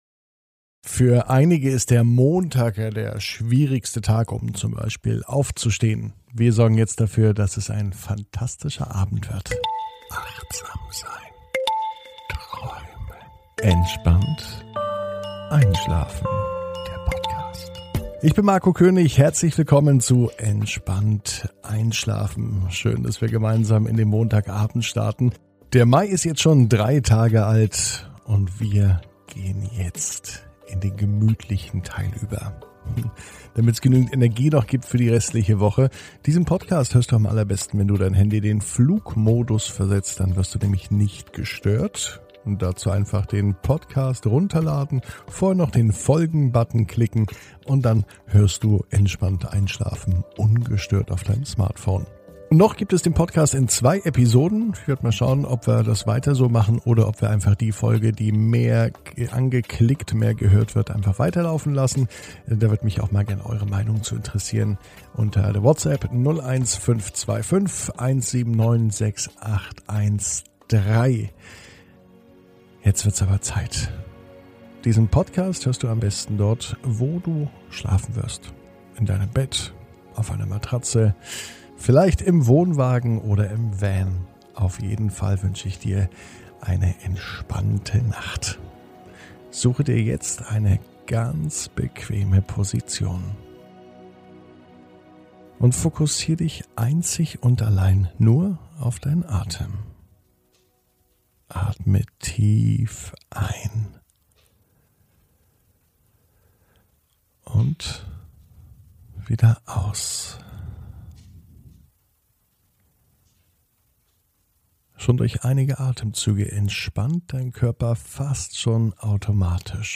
(ohne Musik) Entspannt einschlafen am Montag, 03.05.21 ~ Entspannt einschlafen - Meditation & Achtsamkeit für die Nacht Podcast